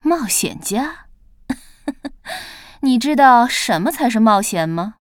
文件 文件历史 文件用途 全域文件用途 Cyrus_tk_03.ogg （Ogg Vorbis声音文件，长度5.0秒，101 kbps，文件大小：61 KB） 源地址:游戏语音 文件历史 点击某个日期/时间查看对应时刻的文件。